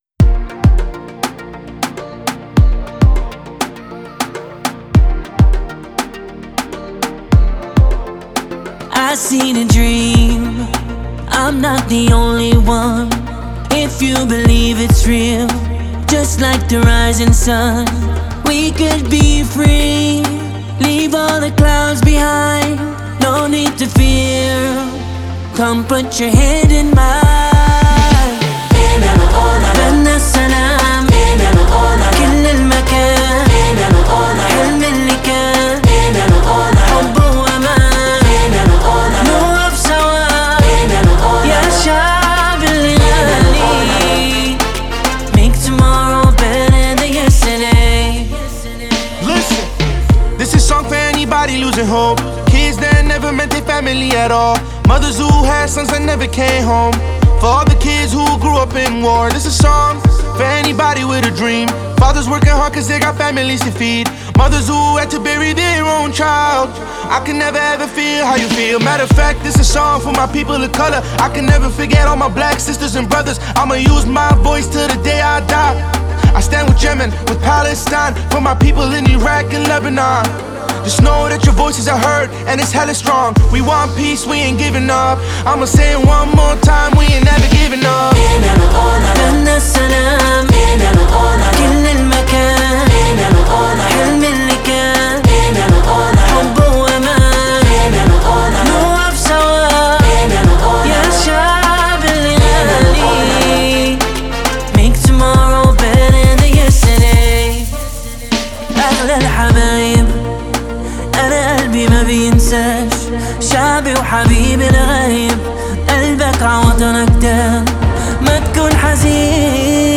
это трек в жанре R&B и поп